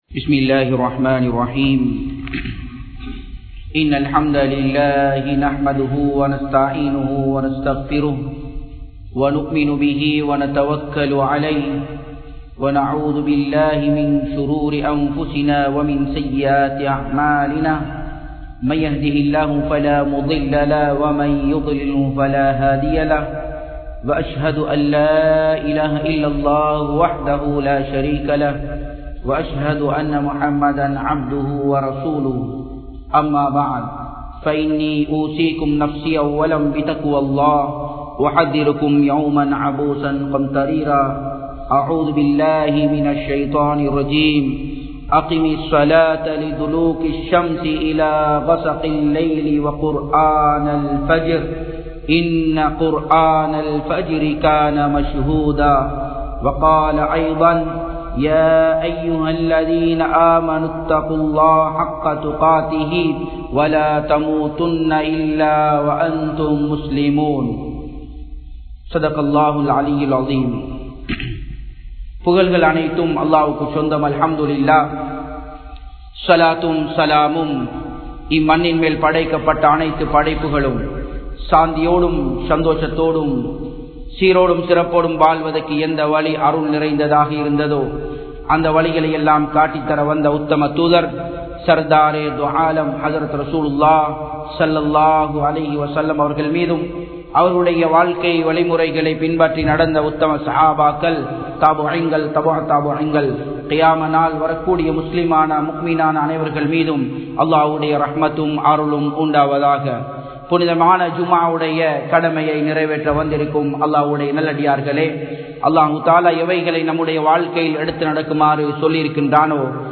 Unmaiyana Selvanthar Yaar? (உண்மையான செல்வந்தர் யார்?) | Audio Bayans | All Ceylon Muslim Youth Community | Addalaichenai
Japan, Nagoya Port Jumua Masjidh